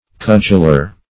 Meaning of cudgeler. cudgeler synonyms, pronunciation, spelling and more from Free Dictionary.
Search Result for " cudgeler" : The Collaborative International Dictionary of English v.0.48: Cudgeler \Cudg"el*er\ (-?r), n. One who beats with a cudgel.
cudgeler.mp3